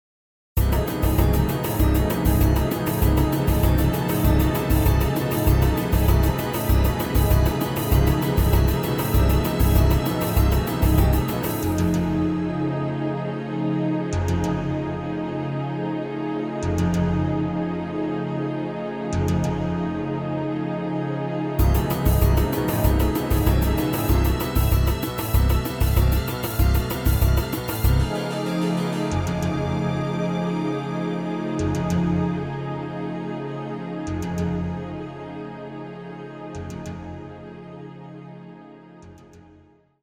very melodic and action packed score